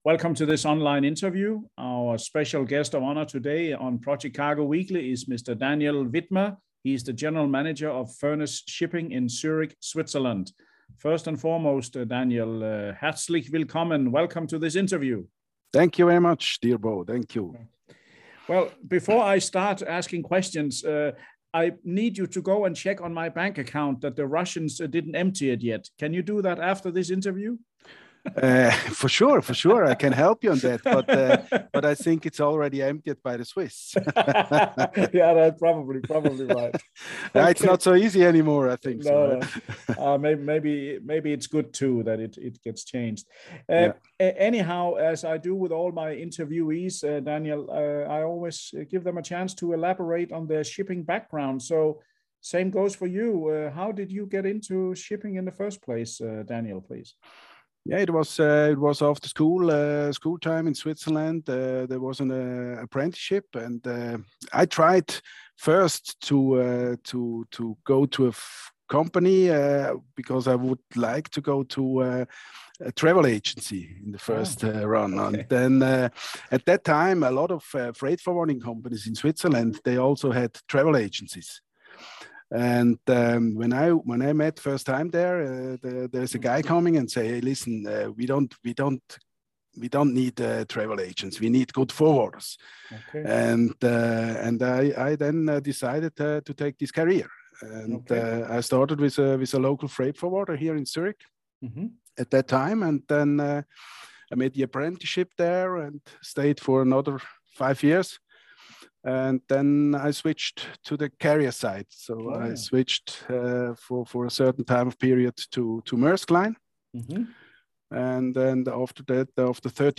Video InterviewFurness Shipping – Zurich, Switzerland